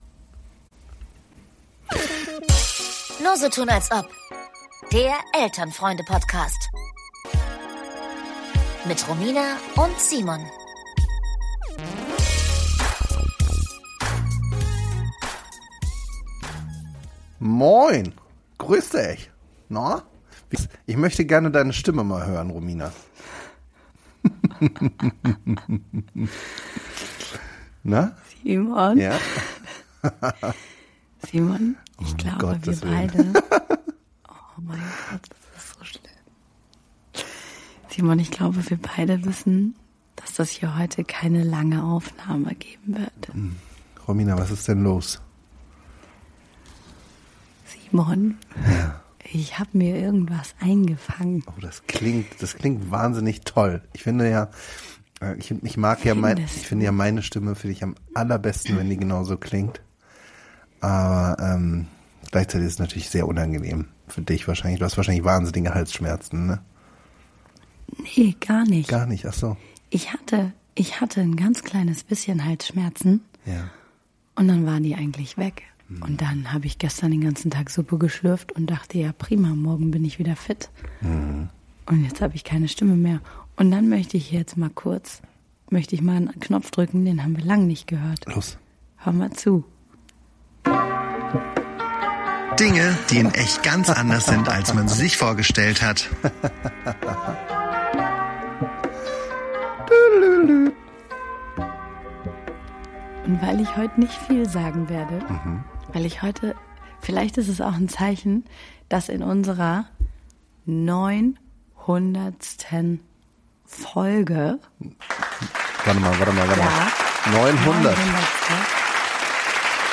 Ändern kann man weder die festgestellte Schmallippigkeit, noch die gut hörbare Kehlkopfentzüdung.